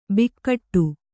♪ bikkaṭṭu